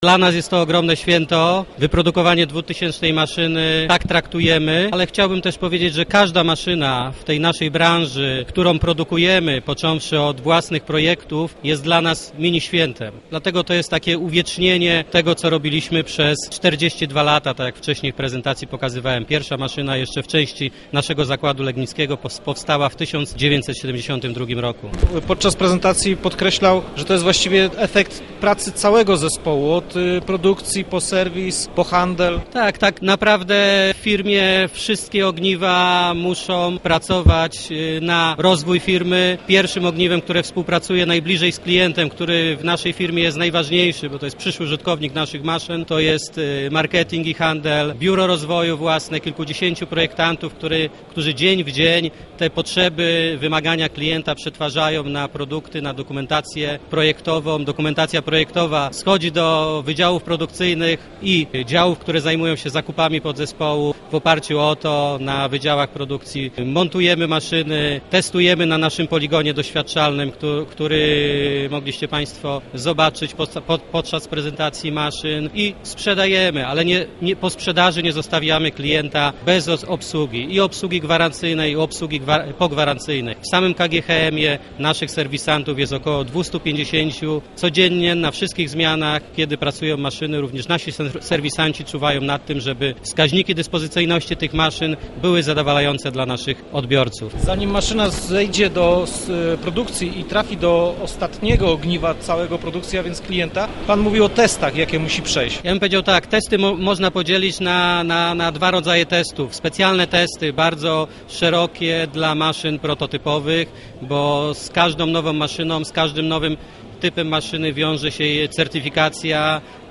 Rozmowy Elki